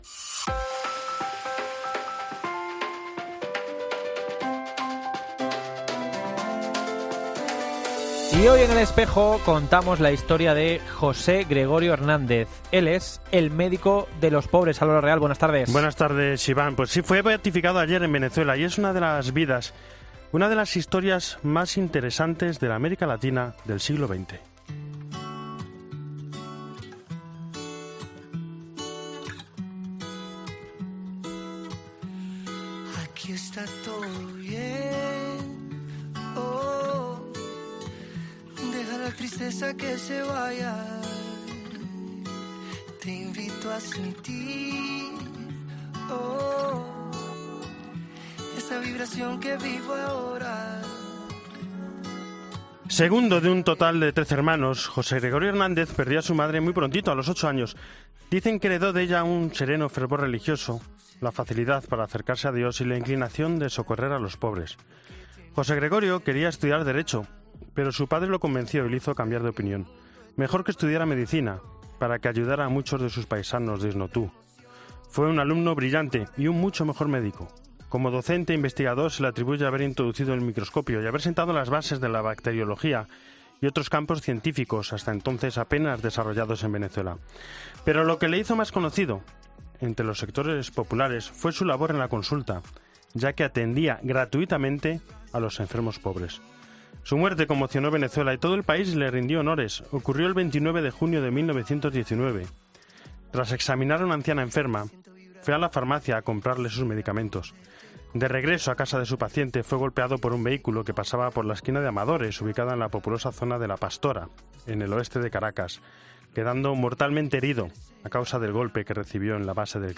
entrevista Madre Ven